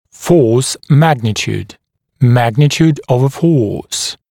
[fɔːs ‘mægnɪt(j)uːd] [‘mægnɪt(j)uːd əv ə fɔːs] [-ʧuːd][фо:с ‘мэгнит(й)у:д] [‘мэгнит(й)у:д ов э фо:с] [-чу:д]величина силы